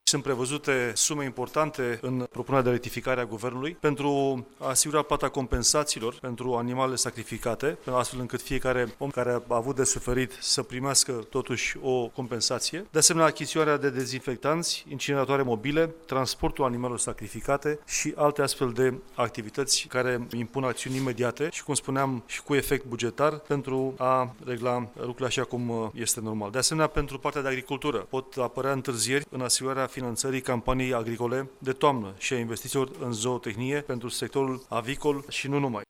La Palatul Victoria, ministrul Finanţelor, Eugen Teodorovici a declarat că orice întârziere a rectificării bugetare poate să creeze efecte efecte negative la nivelul economiei. Potrivit ministrului Teodorovici, în cazul amânării rectificării, luna septembrie ar rămâne neacoperită în ceea ce priveşte plata salariilor pentru o serie de entităţi publice.